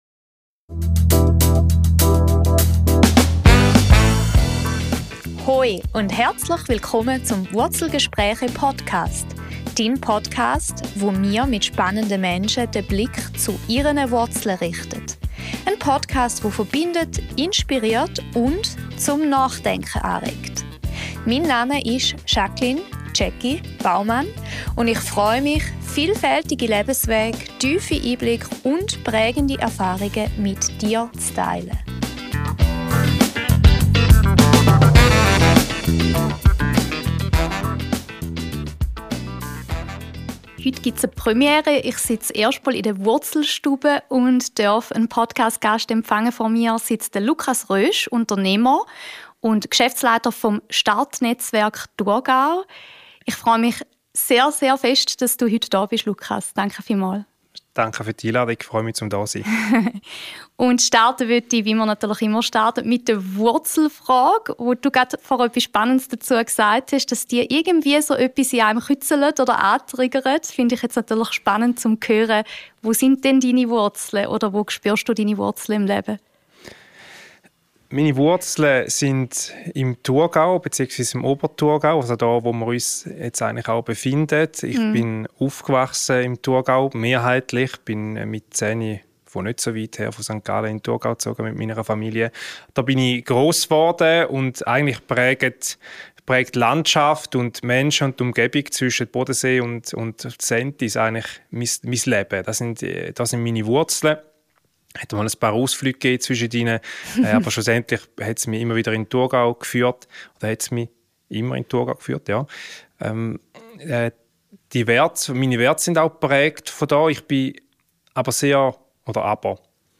Ein ehrliches Gespräch über Unternehmergeist, Verantwortung und den Mut, den eigenen Weg immer wieder neu auszurichten.